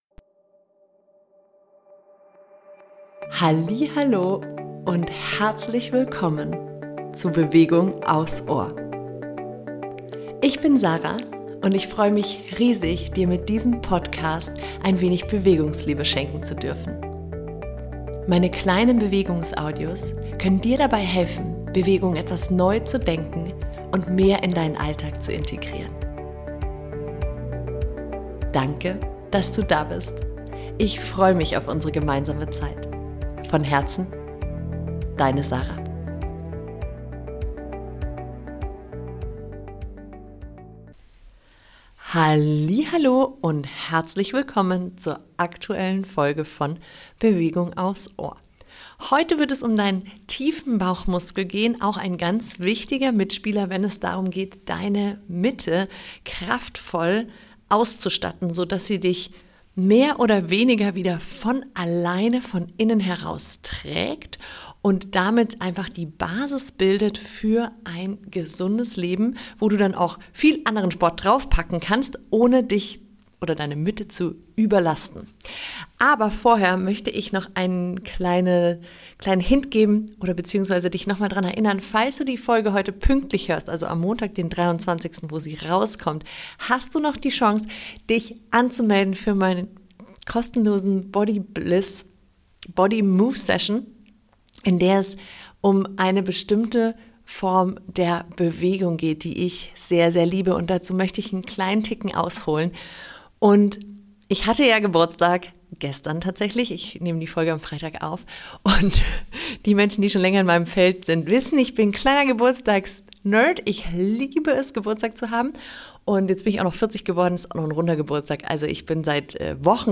Die aktive Übungssession beginnt circa ab Minute 5, davor gibt es noch eine Einladung und persönliche Einblicke in meine Arbeit!